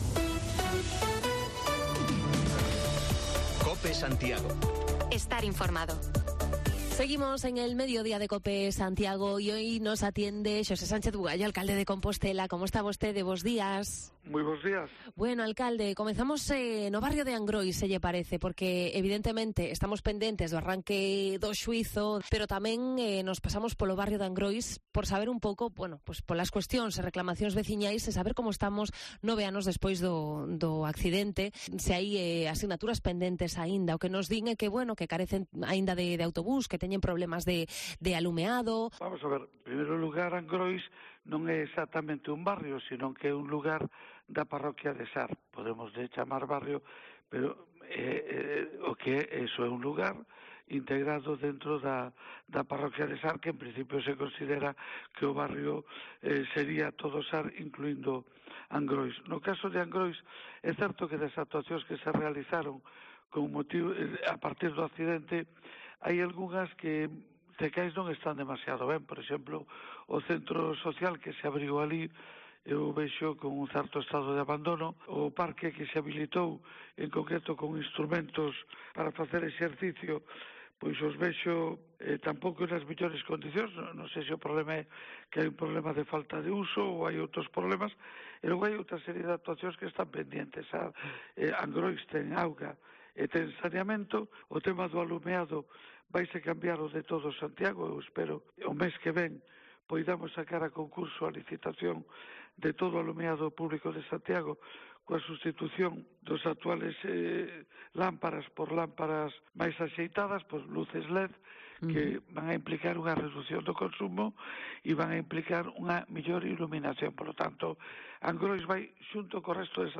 Repasamos la actualidad de Santiago con el alcalde, Sánchez Bugallo: entre otras cuestiones, le preguntamos por las promesas pendientes con el barrio de Angrois